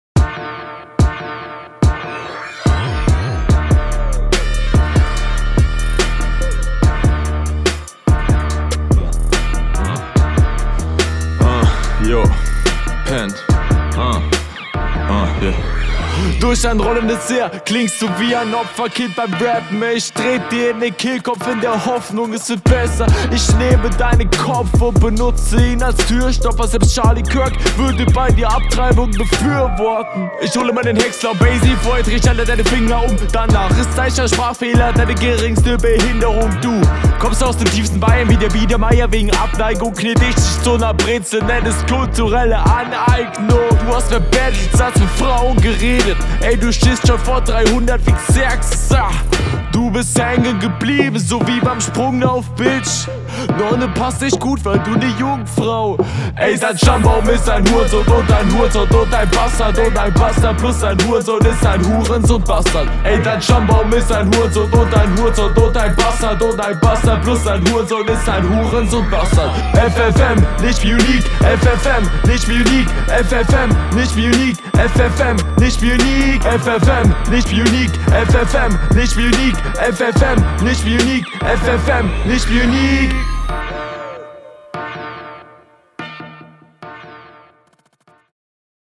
Flow kommt hier besser, viel Abwechslung ist trotzdem nicht drin, aber es geht deutlich mehr …